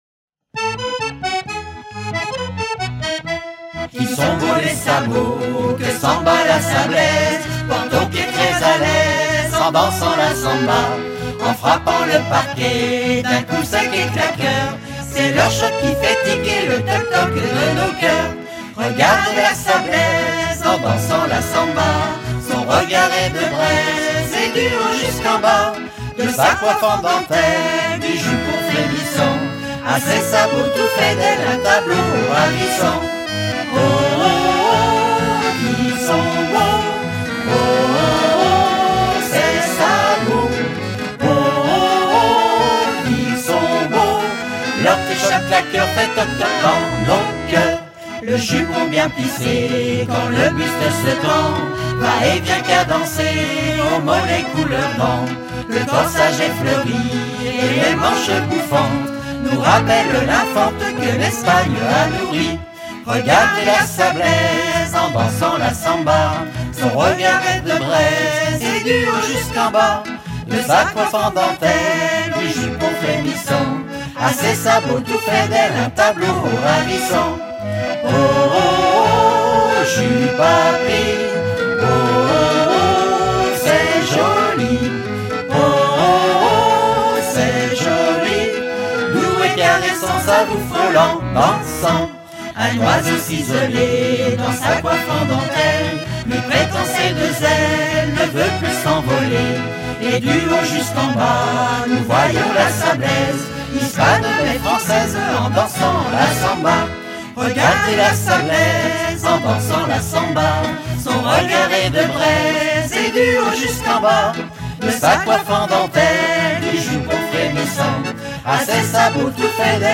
danse : samba
Pièce musicale éditée